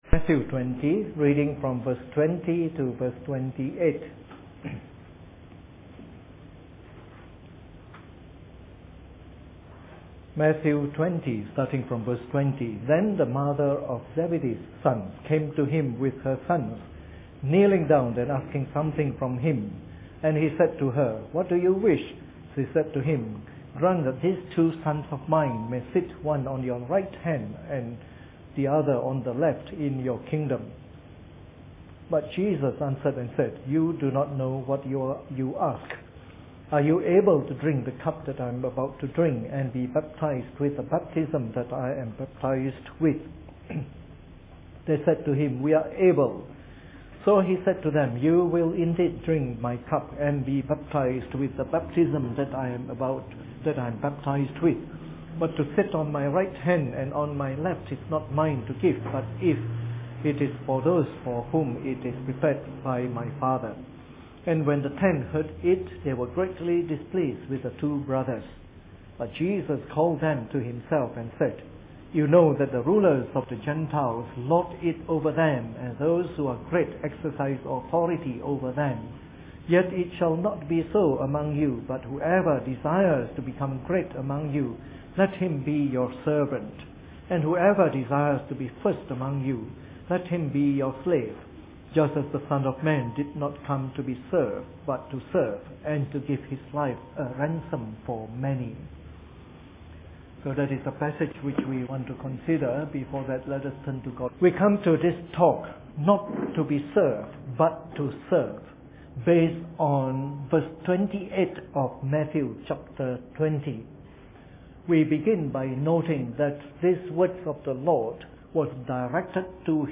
Preached on the 27th of February 2013 during the Bible Study, a message recently given at a conference in Myanmar.